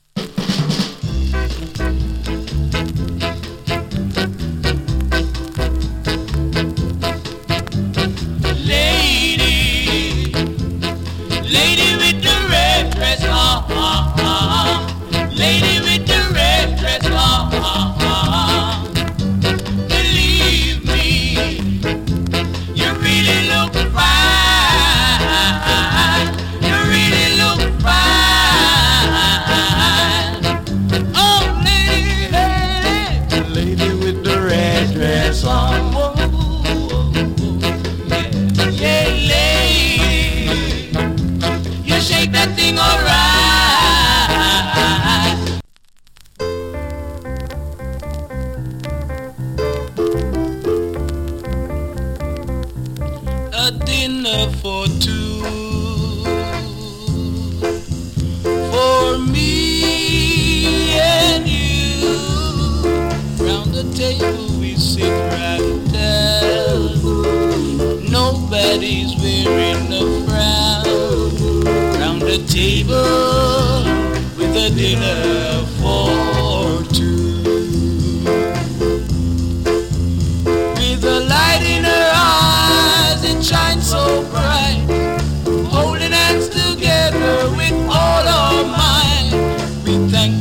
ノイズ数回有り。
チリ、パチノイズ少々有り。
SKA OLD HITS !! & NICE BALLAD 名曲 !